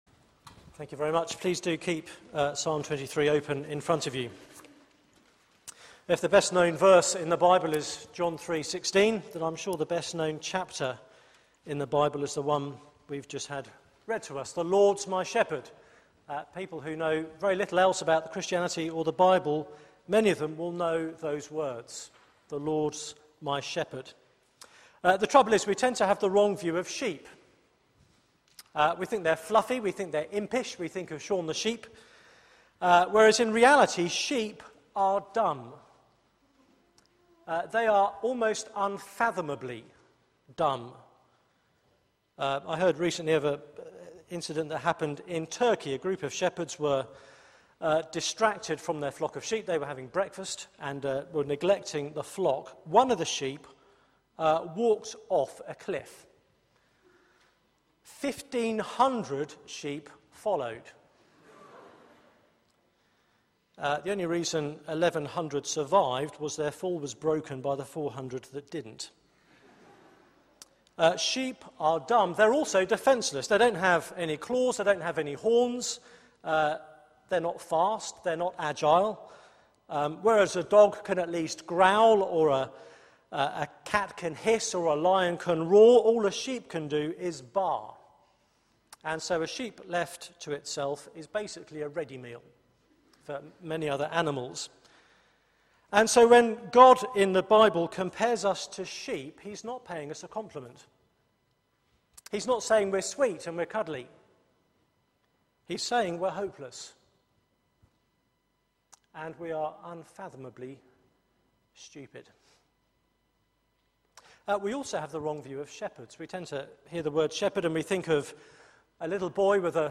Media for 6:30pm Service on Sun 01st Sep 2013 18:30 Speaker
Series: Summer Songs Theme: The all-sufficient shepherd Sermon Search the media library There are recordings here going back several years.